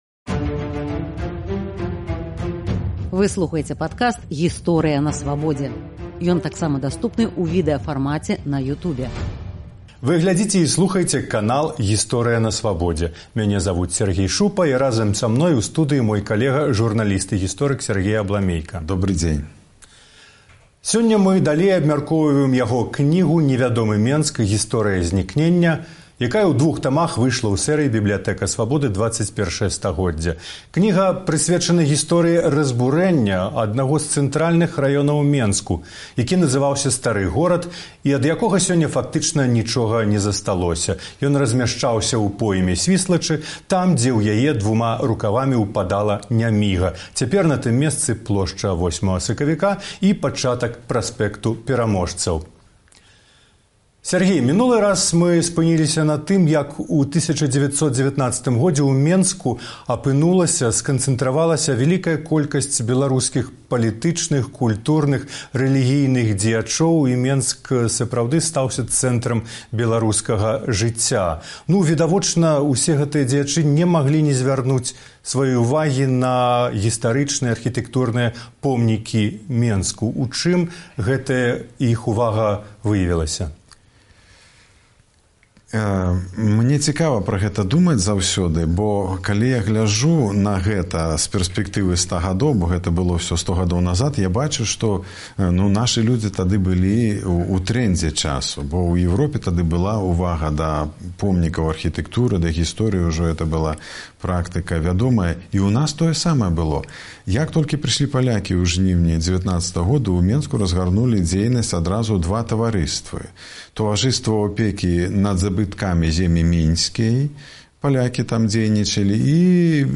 Радыё Свабода пачало ў новым YouTube-канале «Гісторыя на Свабодзе» публікацыю цыклю ілюстраваных відэагутарак пра Стары Менск.